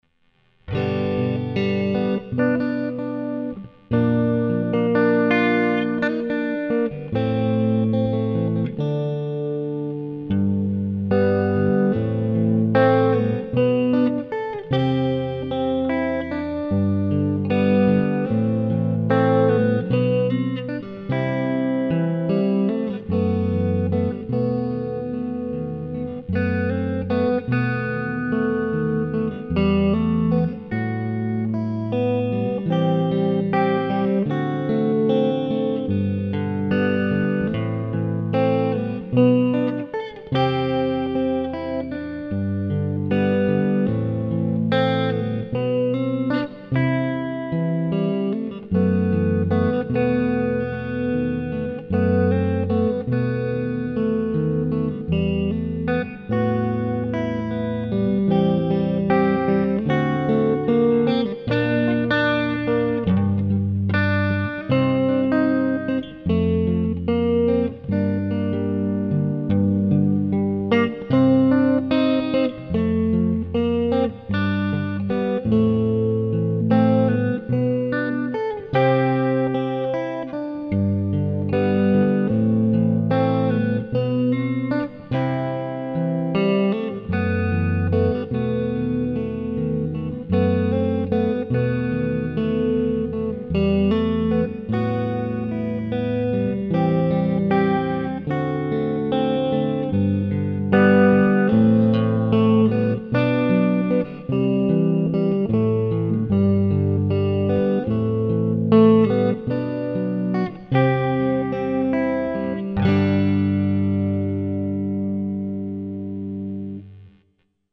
mooi en goed geluid!